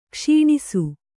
♪ kṣīṇisu